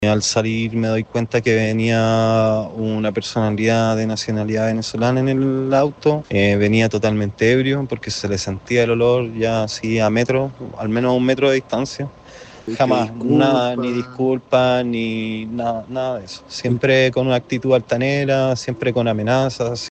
Un vecino del sector comentó que al bajar a ver la situación, el conductor y los ocupantes lo agredieron verbalmente. Además, lo amenazaron para que no diera aviso a la policía.
cu-trasnoche-2-vecino.mp3